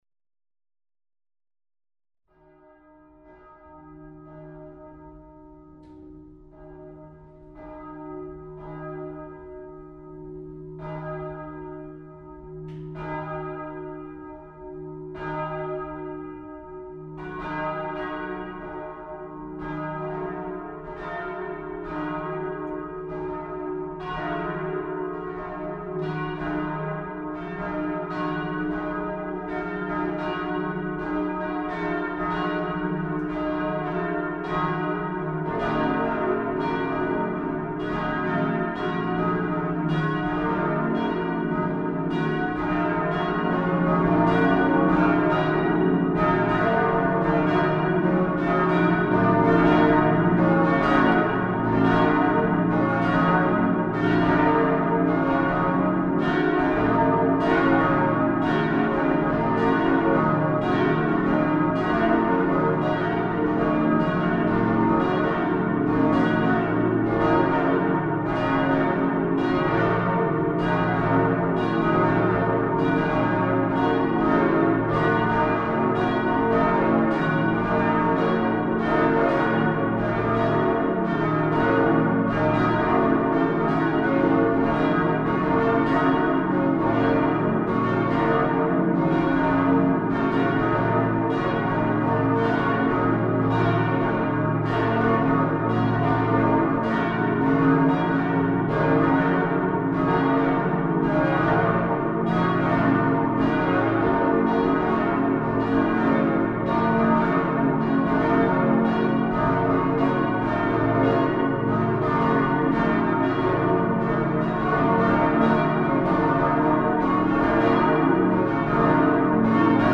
Glocken_Antonius_1999.mp3